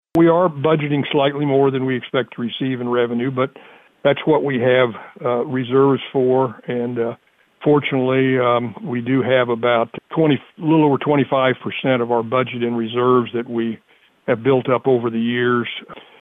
Douglas says this year they will be relying on some of their reserves.